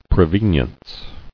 [pre·ven·ience]